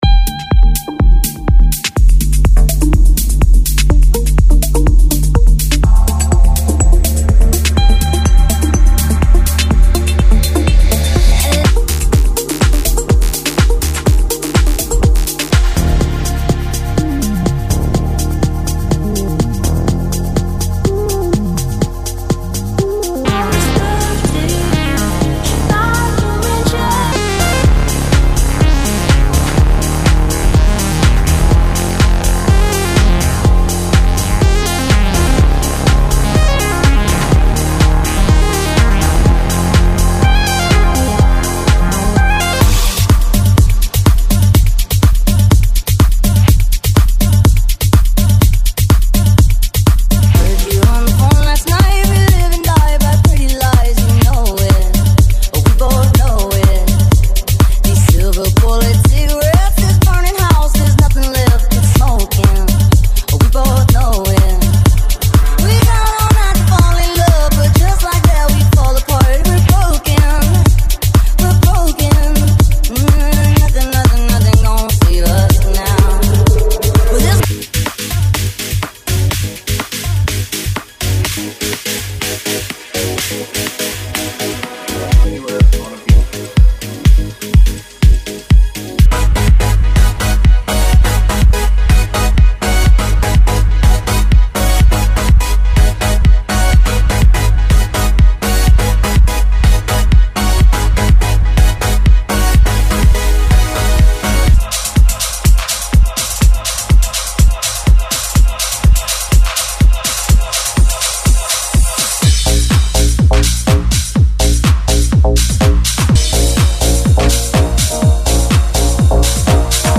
*** House